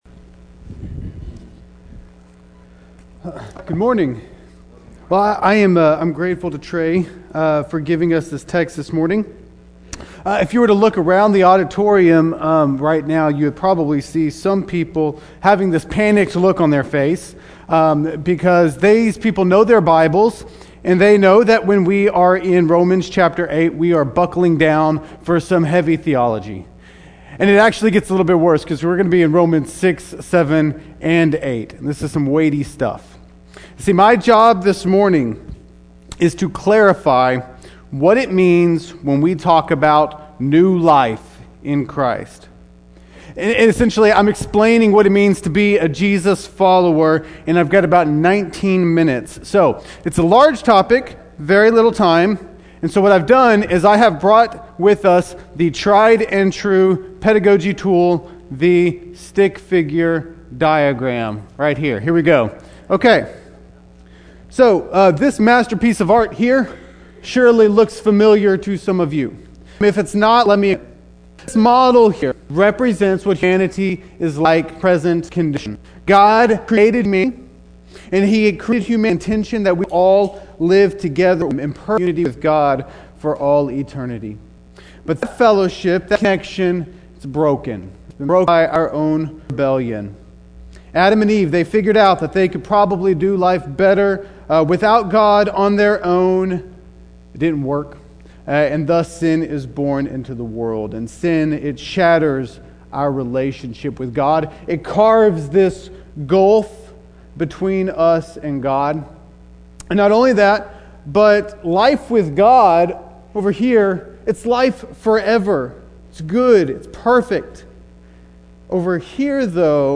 Weekly Sermon Audio “What We Believe About…